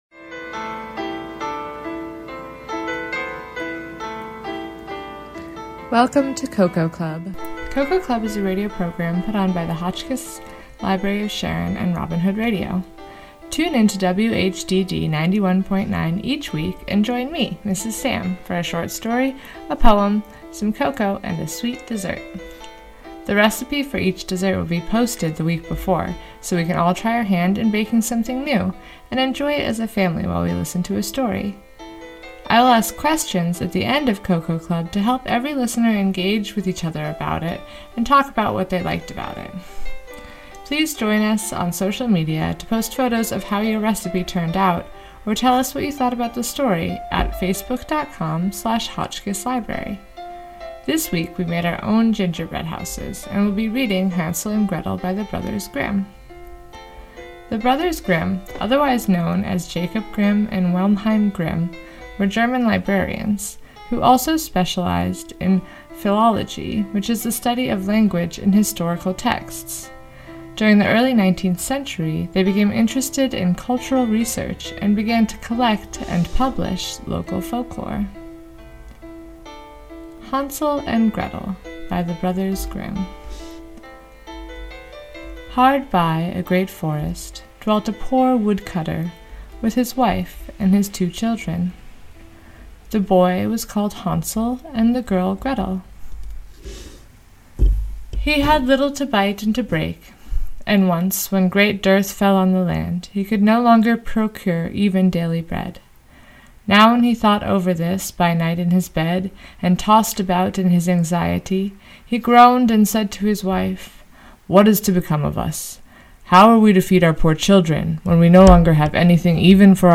Hotchkiss Library of Sharon Cocoa Club Reading: The Brothers Grimm Classic "Hansel and Gretel"